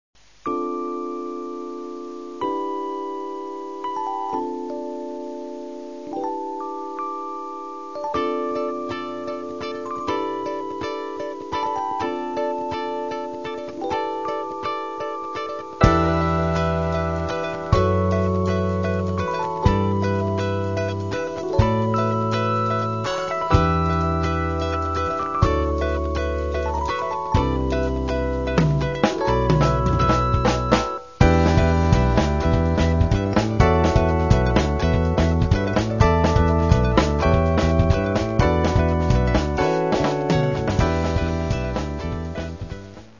Каталог -> Джаз та навколо -> Міські суміші
акустична гітара
бас
ударні, перкусія